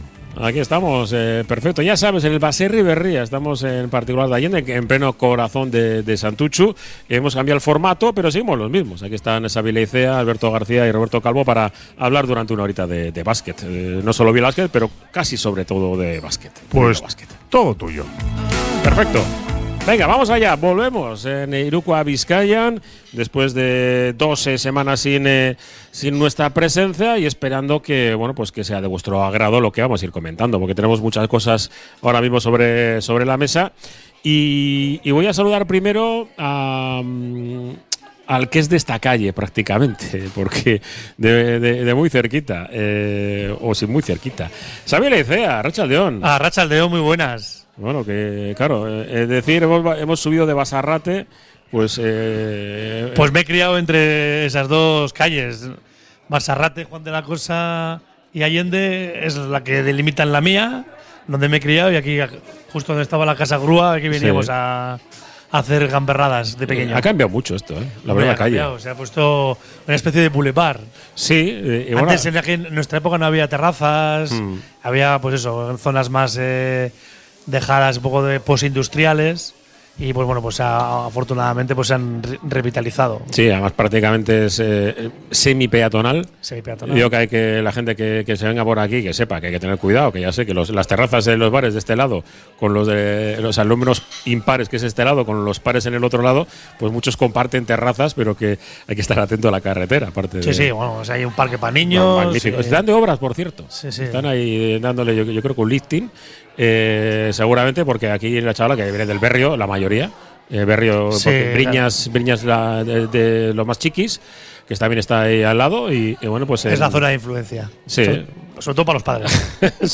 Desde el Baserri Berria de Santutxu